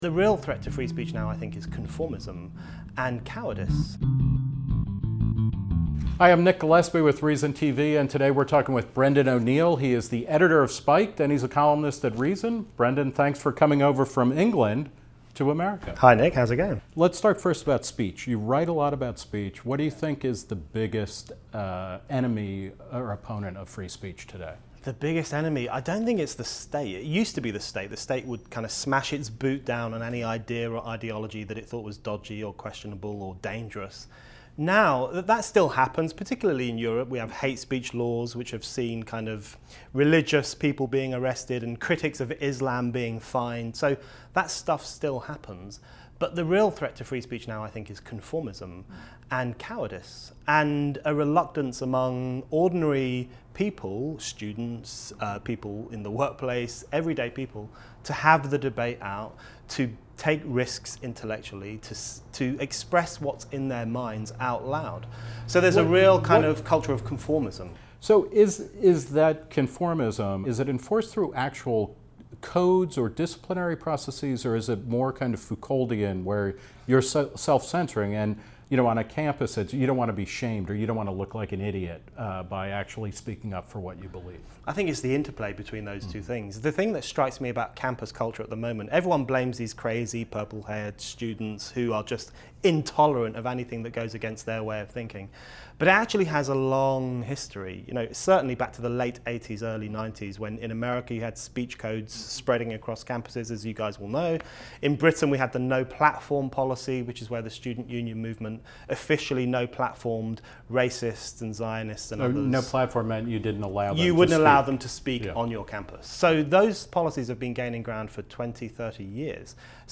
The Reason Interview